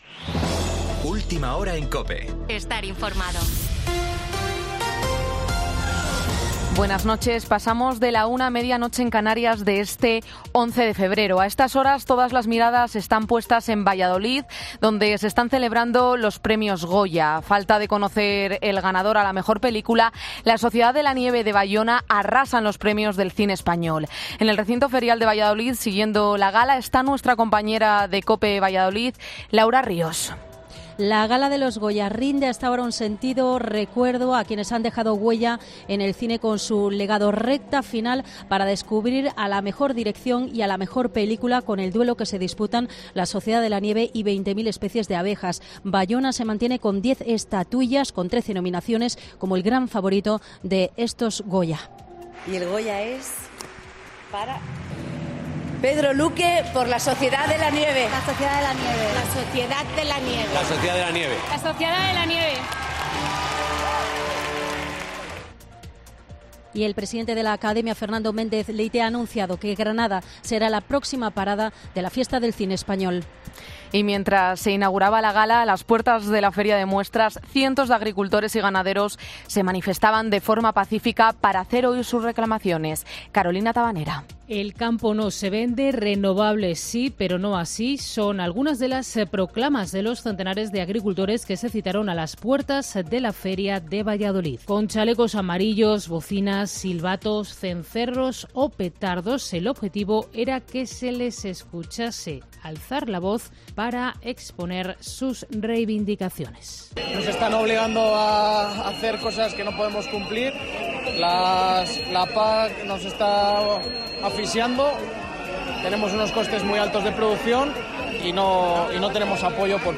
Boletín 01.00 horas del 11 de febrero de 2024